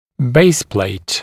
[‘beɪspleɪt][‘бэйсплэйт]базисная пластинка (съемного аппарата)